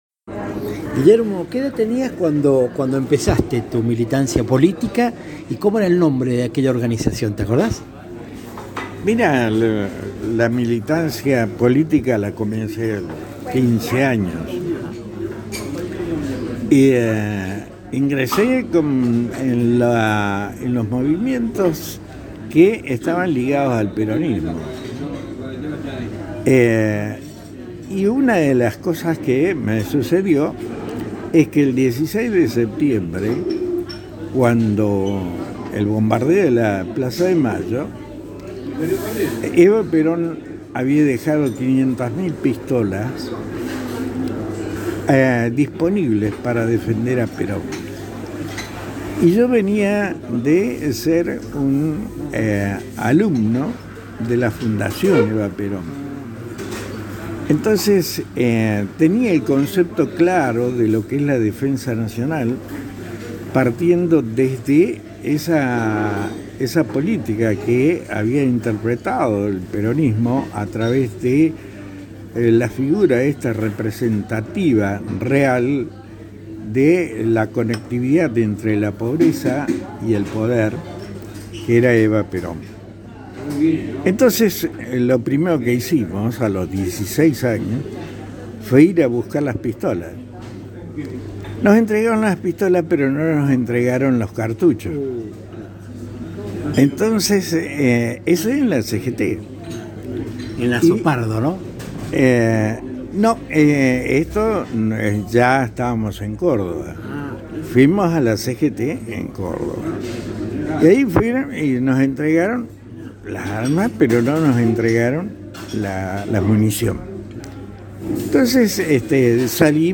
El resto de la anécdota podrás escucharla a tris de un clic, en la entrevista que encontrarás líneas abajo.
Buenos Aires vibra y eso se advierte claramente en esta populosa esquina de Avenida de Mayo y Florida. Sentados a una mesa de la bella confitería London City, los temas se sucedieron frescos, como si se tratara de situaciones recién acontecidas.